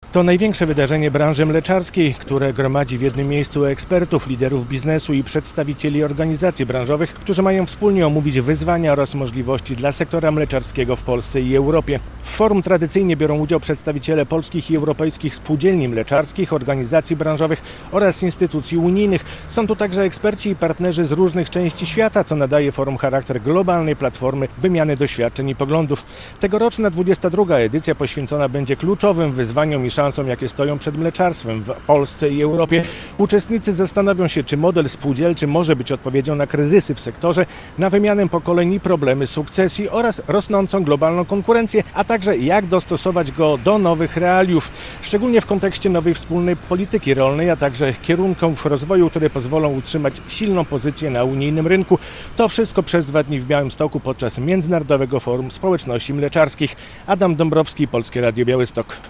22. Międzynarodowe Forum Społeczności Mleczarskiej - relacja